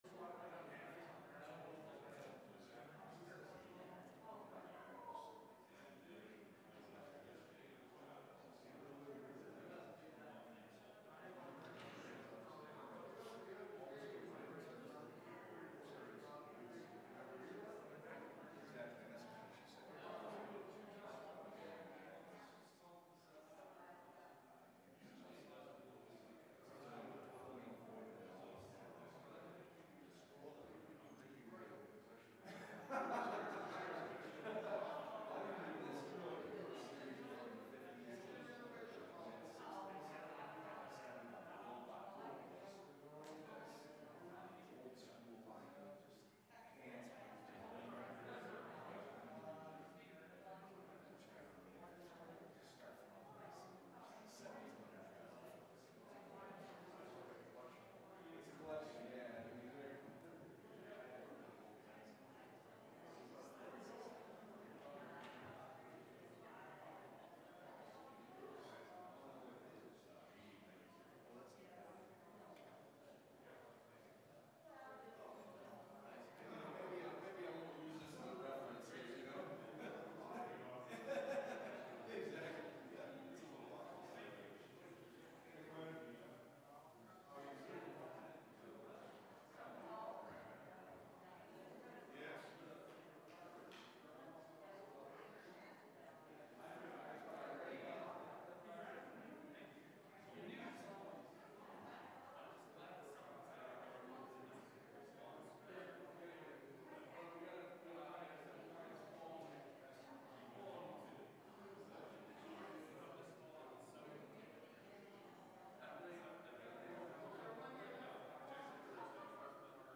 STARTS AT 7:44 MINUTES PRAYER SERVICE